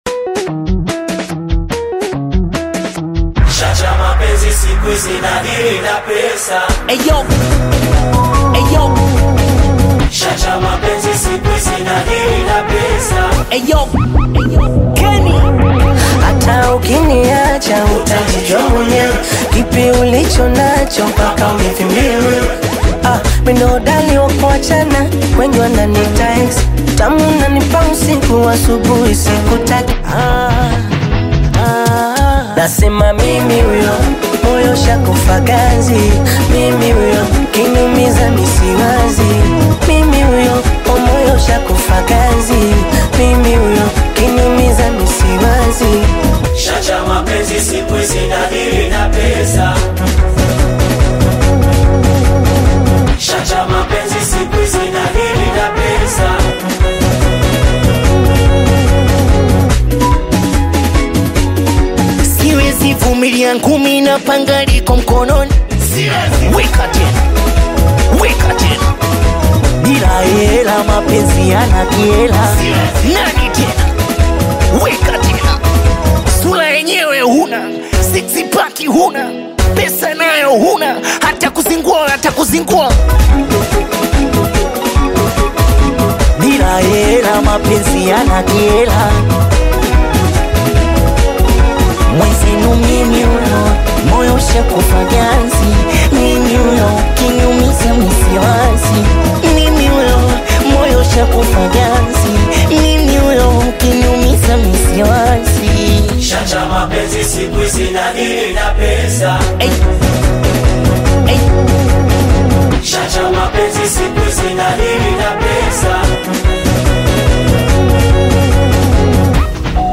Bongo Flava
Singeli